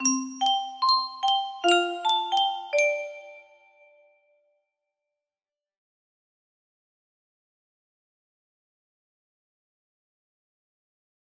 Værelsessang music box melody